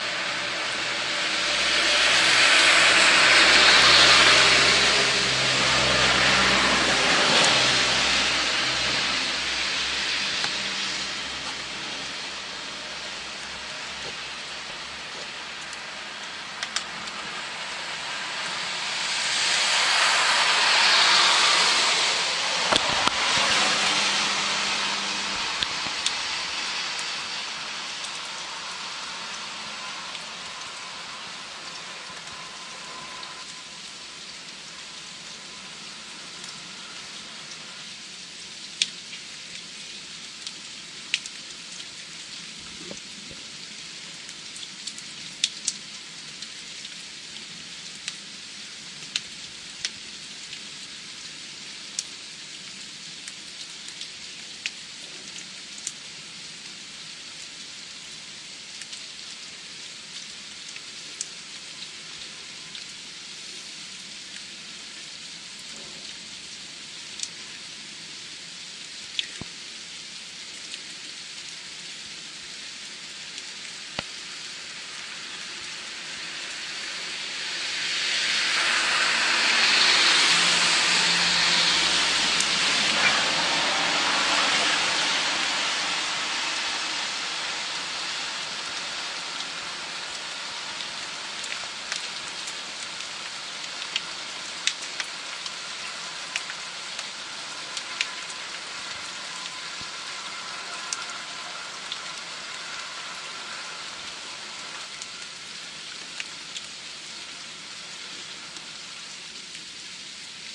2017年7月23日的雷雨之夜 " 雨夜有车经过 2017年7月23日
描述：2017年7月23日（凌晨2点30分）在雷雨期间，当雨水流过城镇时，汽车经过我的房子前面。